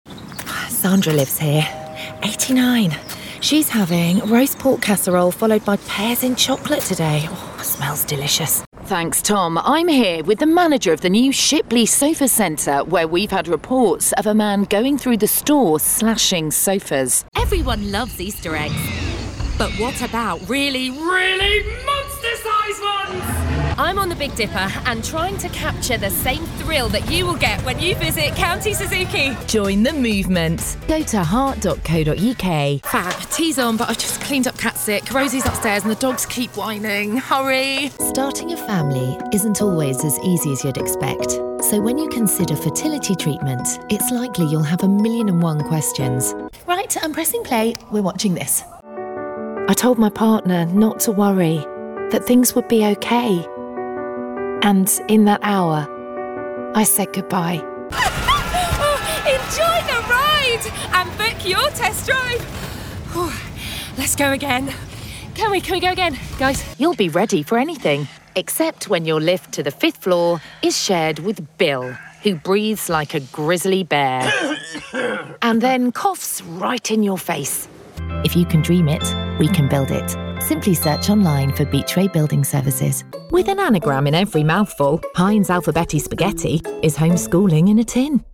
20 Second Soundbite
Female
Neutral British
Down To Earth
Husky (light)